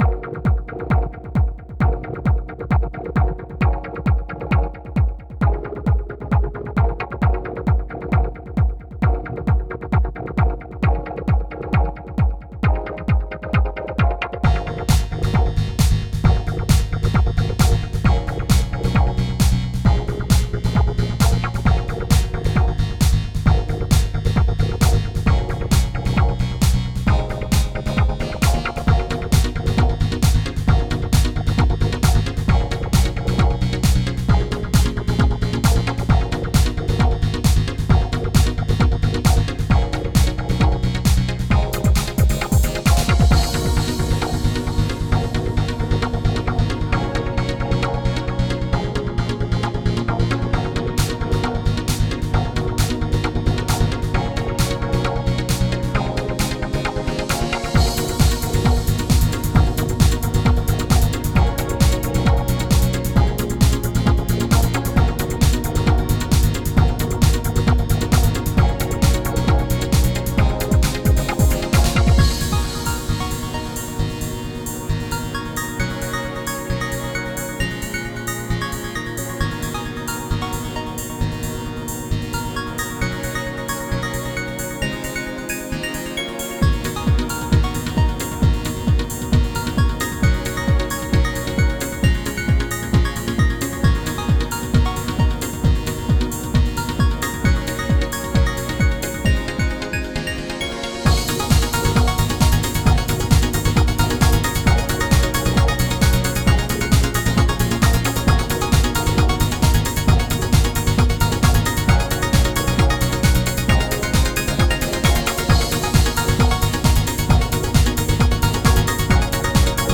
Simple House